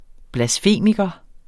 Udtale [ blasˈfeˀmigʌ ]